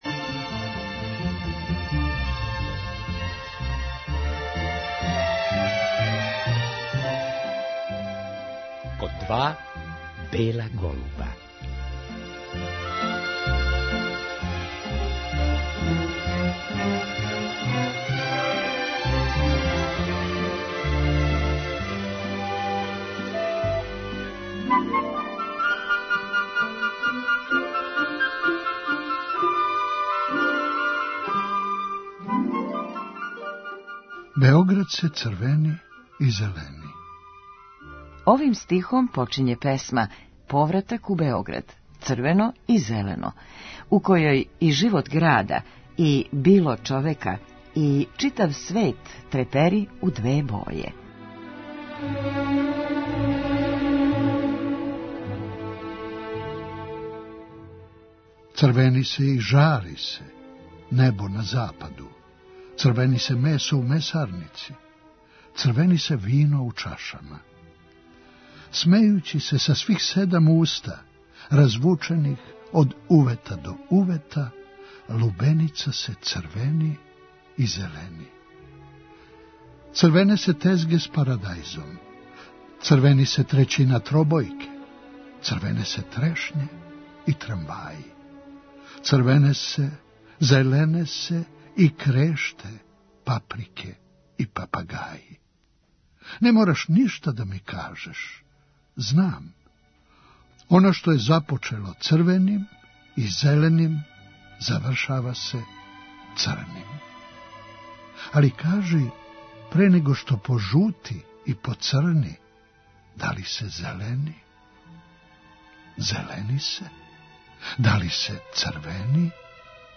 Реприза